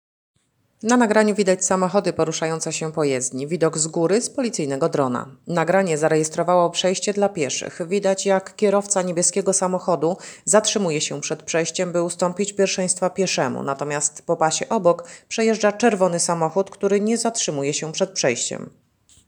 Nagranie audio audiodeskrypcja.wav